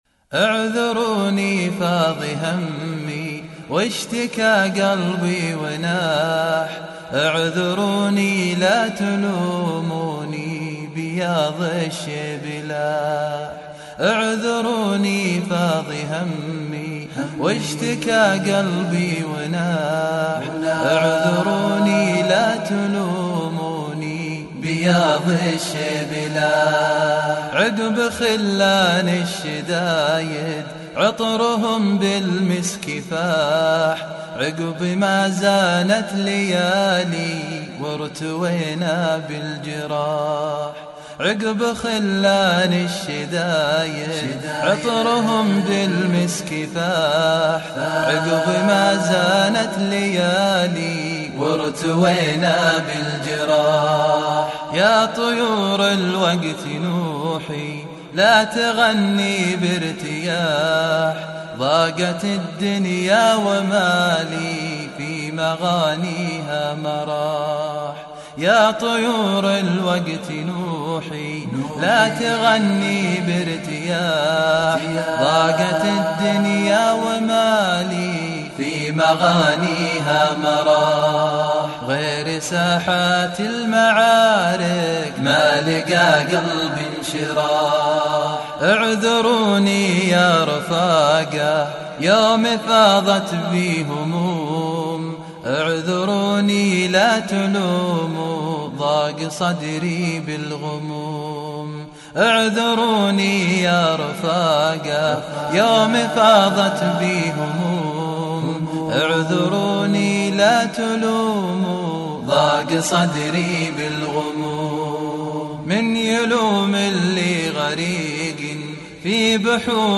اعذروني فاض همي [ بدقّة رائعة ] !
إنشاد